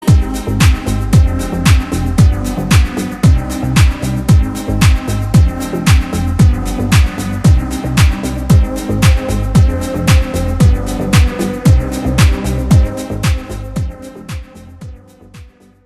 deep house
атмосферные
спокойные
без слов